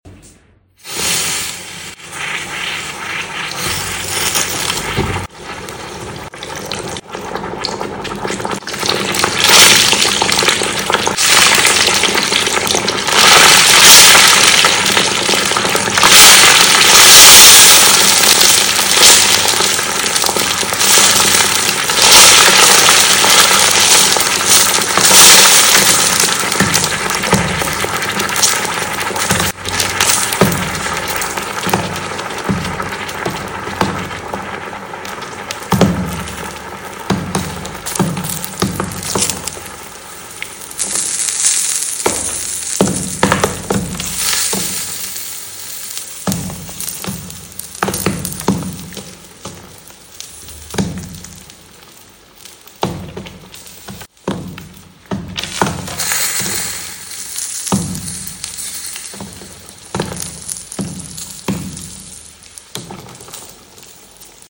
1000°C Red Hot Metal Ball Sound Effects Free Download